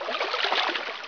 breaststroke.wav